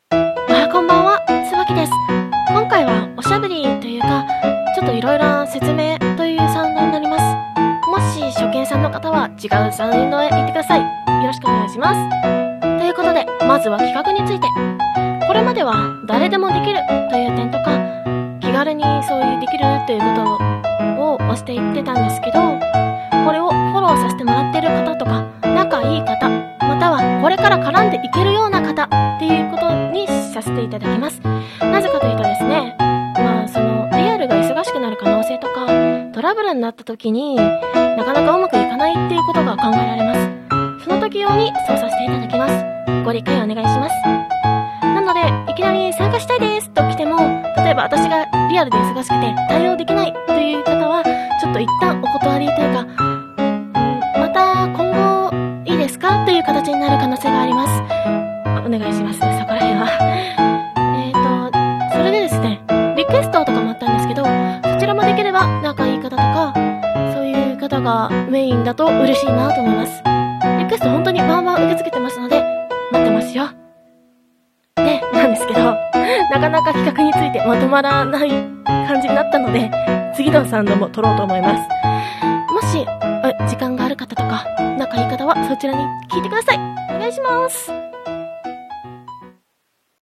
おしゃべりサウンド〜企画について〜